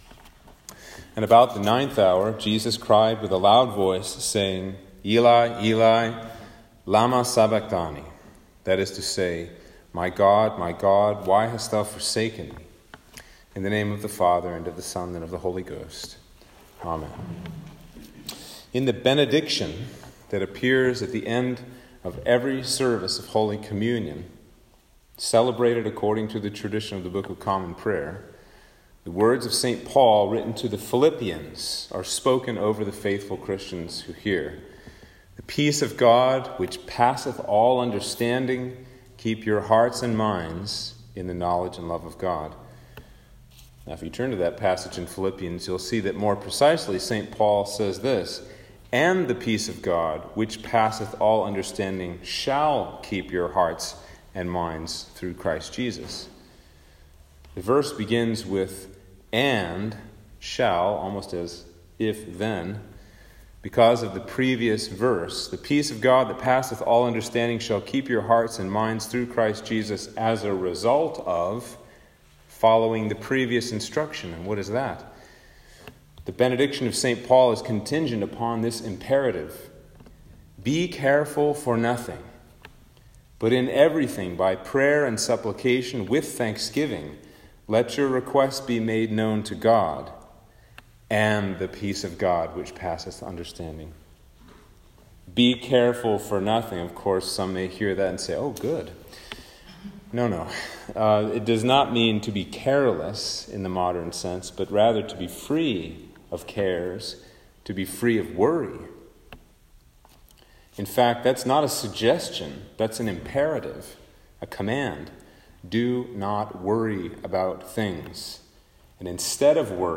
Sermon for Palm Sunday
Sermon-for-Palm-Sunday.m4a